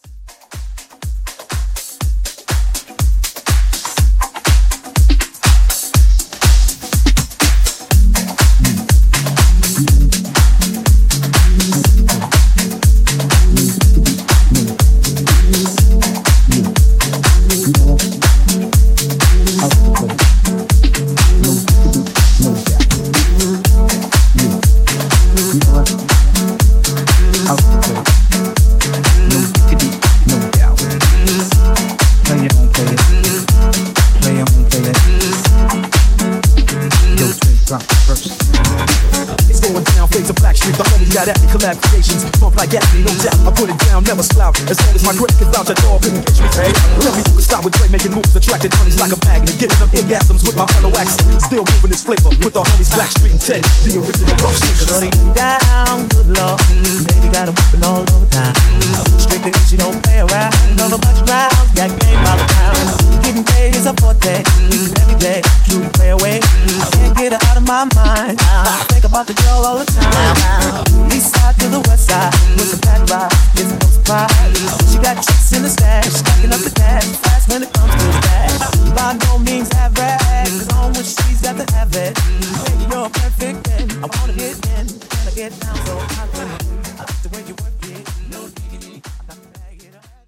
Genre: 70's
Clean BPM: 124 Time